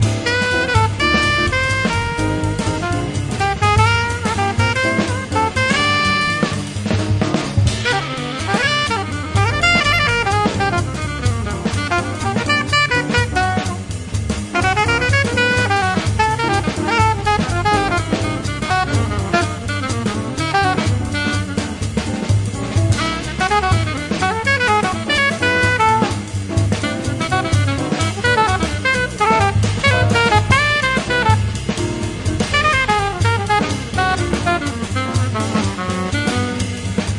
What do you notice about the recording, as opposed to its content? Recorded Premises Studios, London December 7-9th 1993